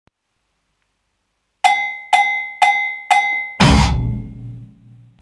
A czasem nawet jest twórczość radosna, spontaniczna i z spod bladego czerepu wyjęta :) Jak np. te dwa, zaawansowane kawałki (UWAGA: jakość próbna).